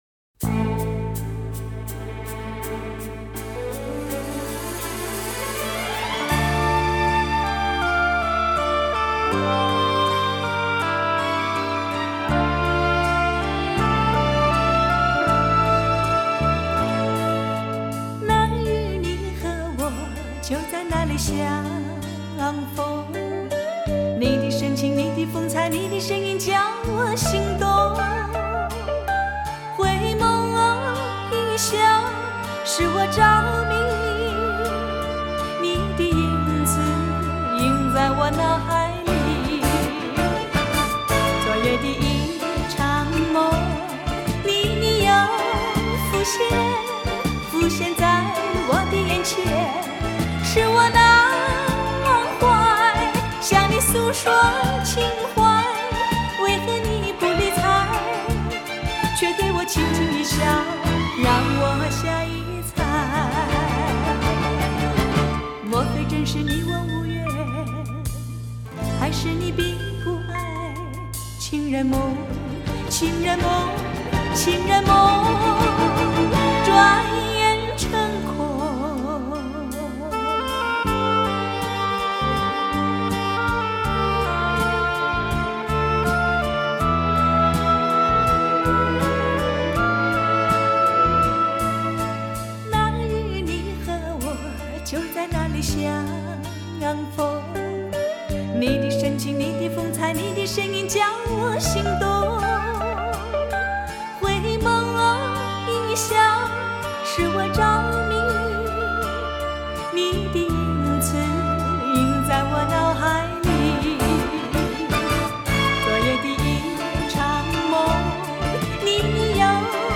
以当今至高规格音效处理